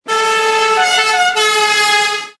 togtuting.mp3